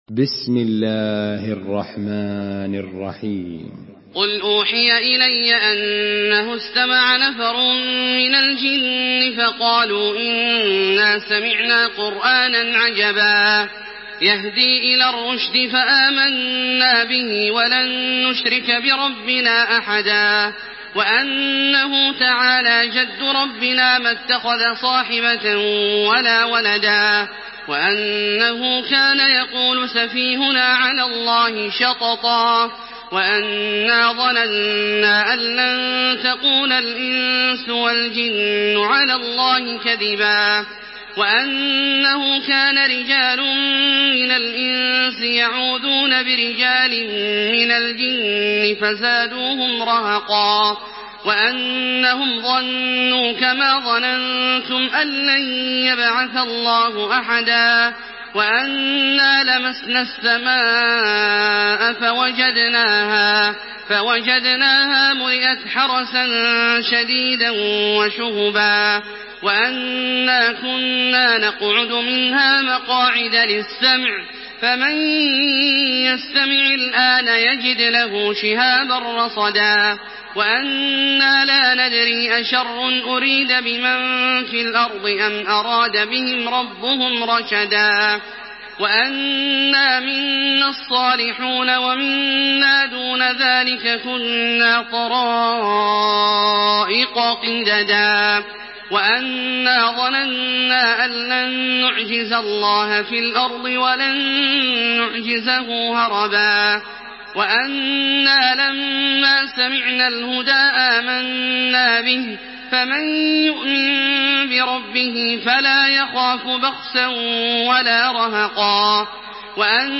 تحميل سورة الجن بصوت تراويح الحرم المكي 1428
مرتل حفص عن عاصم